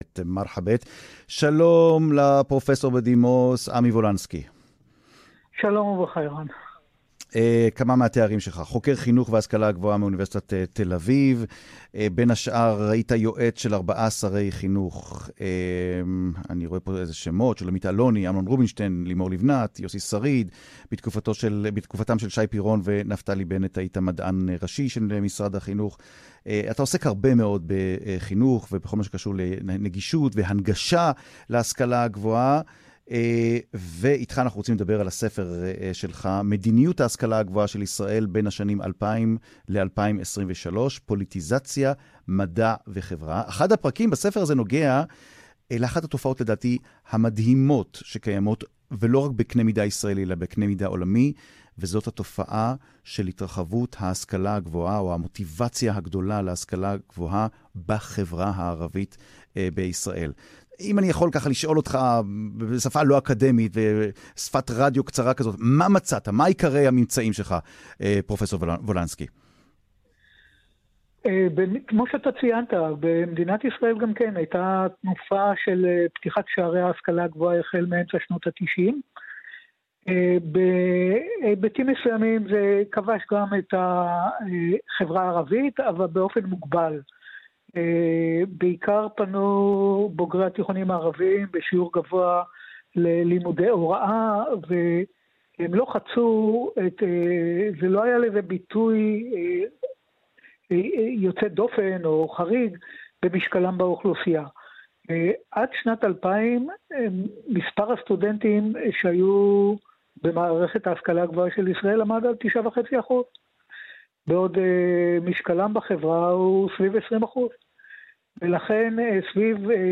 "מרחאבית", ריאיון